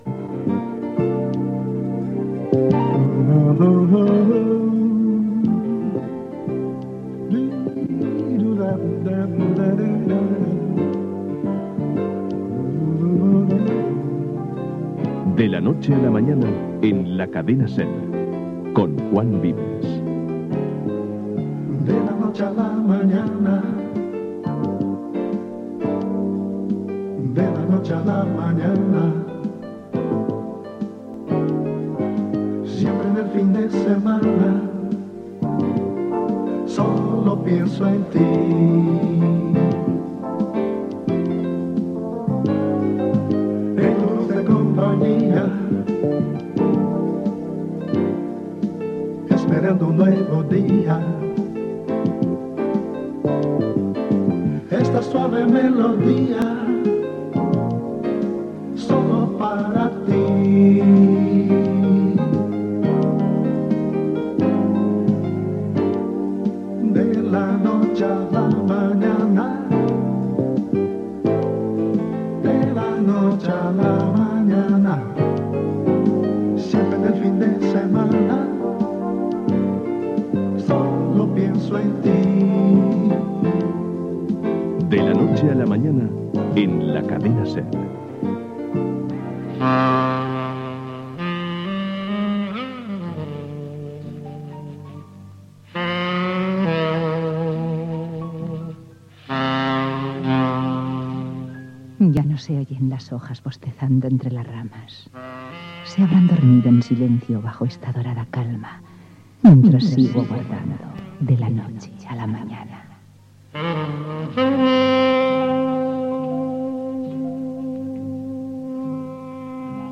Identificació i sintonia del programa, inici de l'espai, música, poema, música i poema.
Entreteniment